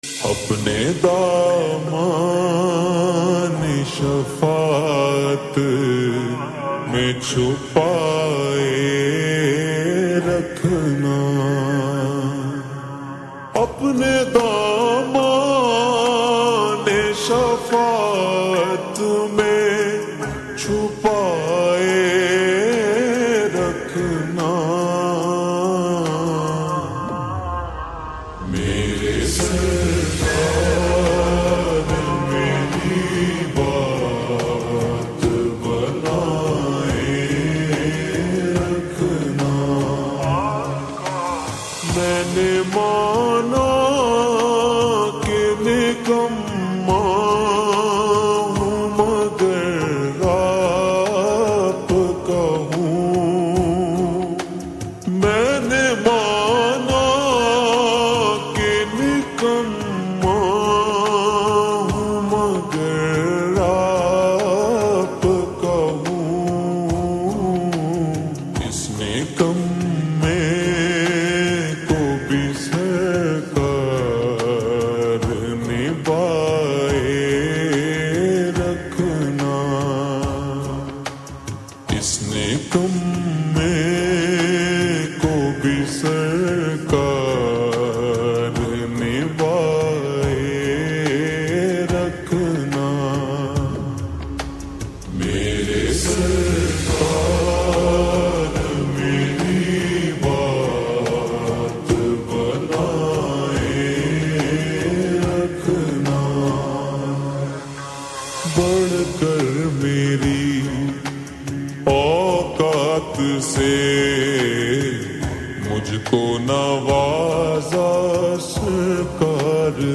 Trending Naat Slowed Reverb Naat